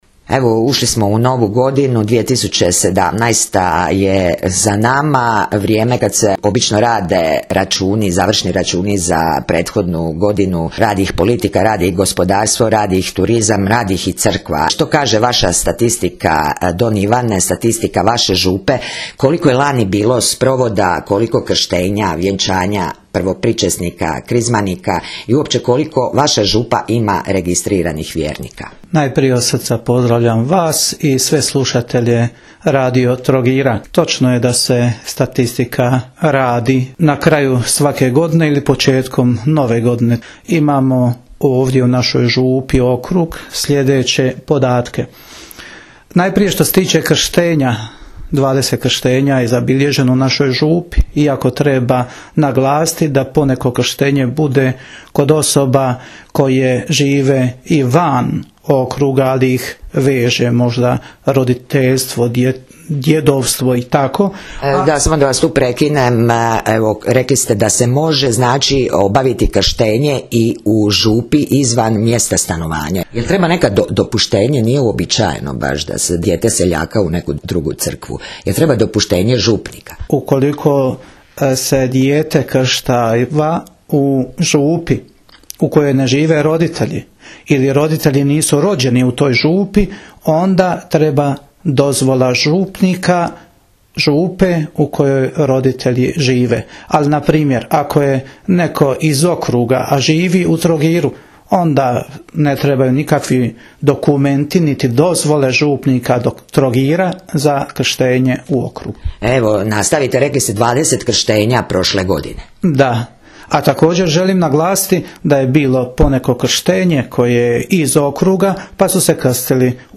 Razgovor
intervju